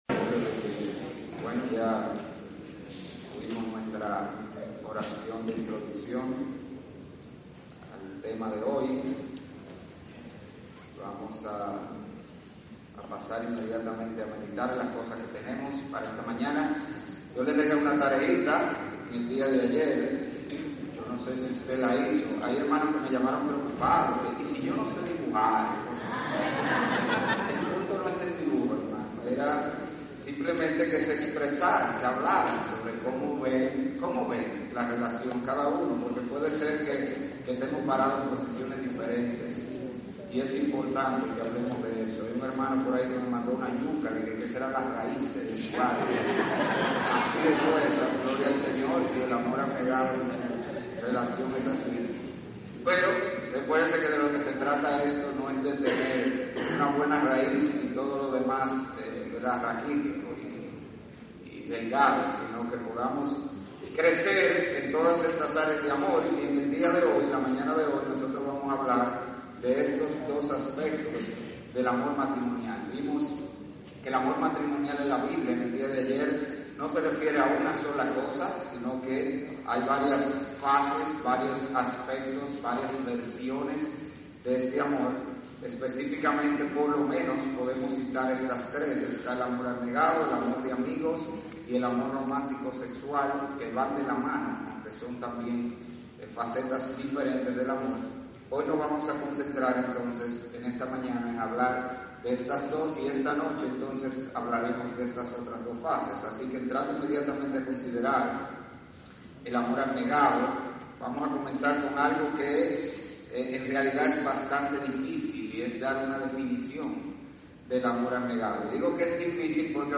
Exposicion # 2 – En esta 2da conferencia hablemos sobre el amor abnegado, su origen e implicaciones, y sobre 4 pilares importantes de su matrimonio NOTA: La calidad del audio pudiera percibirse comprometida, debido a que es un audio de ambiente.